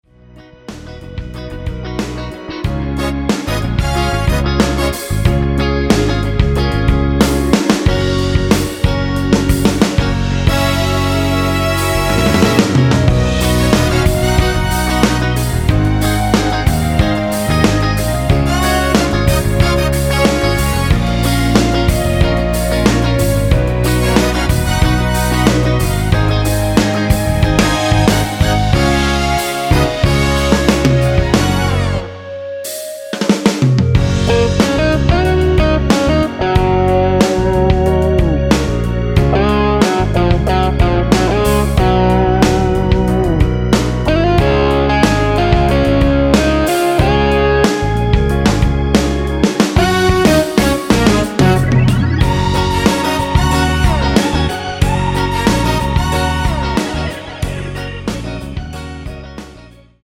원키에서(-1)내린 멜로디 포함된 MR입니다.(미리듣기 확인)
Gb
앞부분30초, 뒷부분30초씩 편집해서 올려 드리고 있습니다.
중간에 음이 끈어지고 다시 나오는 이유는